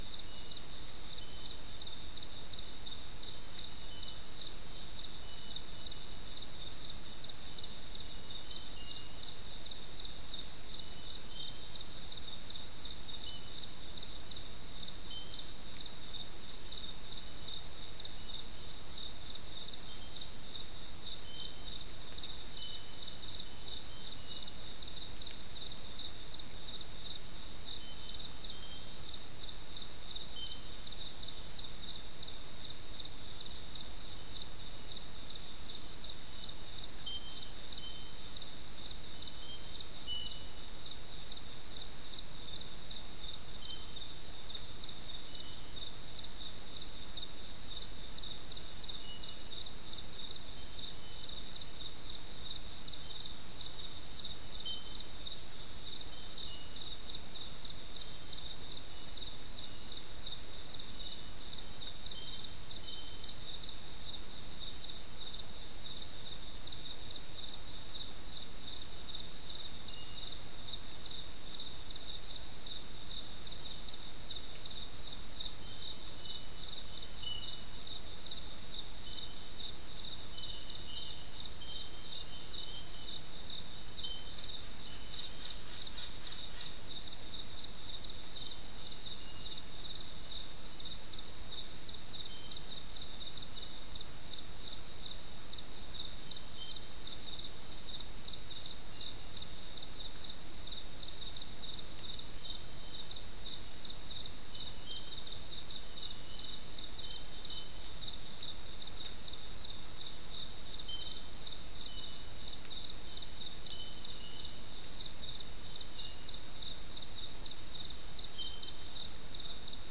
Non-specimen recording: Soundscape
Location: South America: Guyana: Turtle Mountain: 3
Recorder: SM3